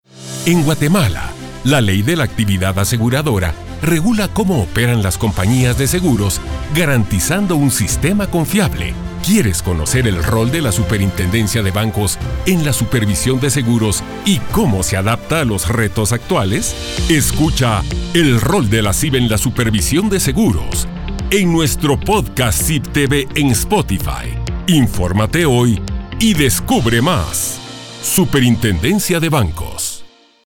Anuncios en Radio